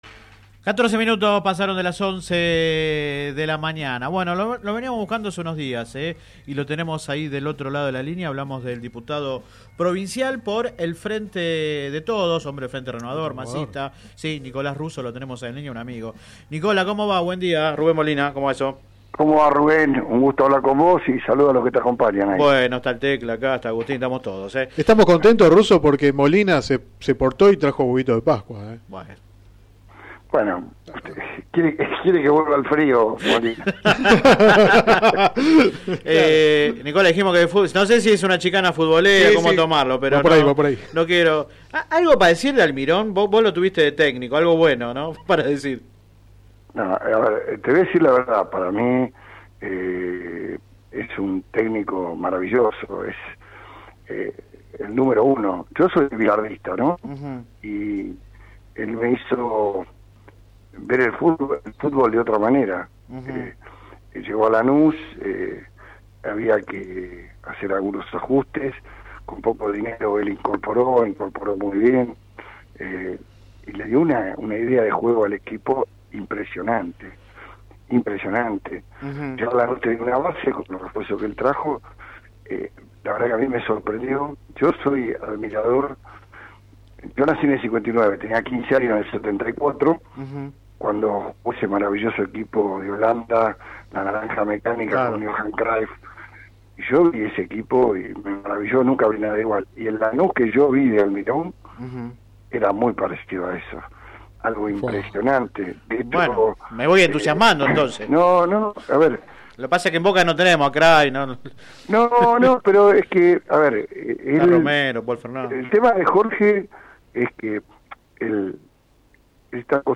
En diálogo con el programa radial Sin Retorno (lunes a viernes de 10 a 13 por GPS El Camino FM 90 .7 y AM 1260) consideró que la actitud del funcionario “fue imprudente” y calificó de “desproporcionada” la forma en que detuvieron a los colectiveros que lo agredieron.
Click acá entrevista radial